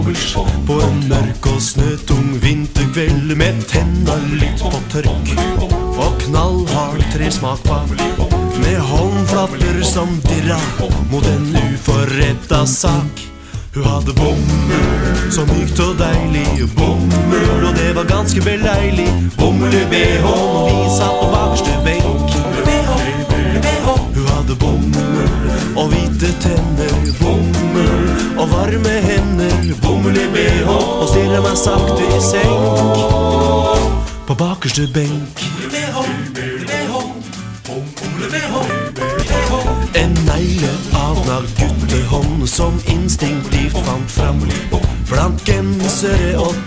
hovedvokal